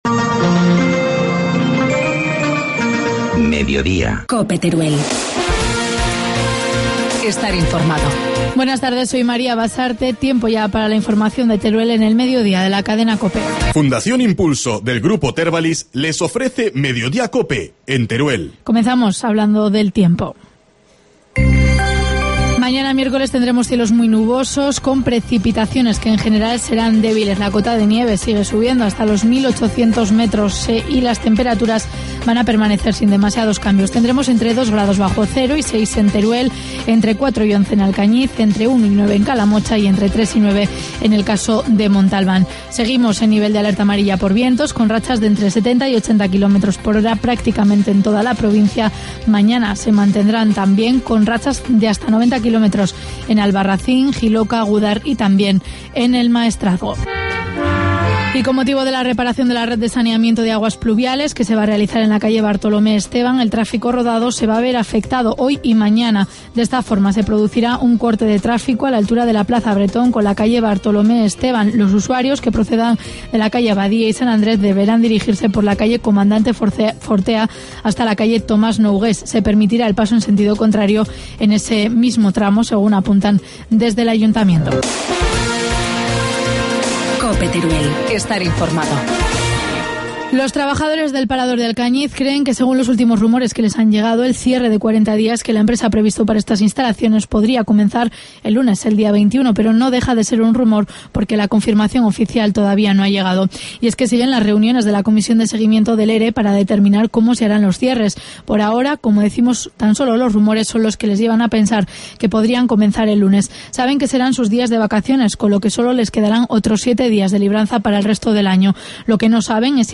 Informativo mediodía, martes 15 de enero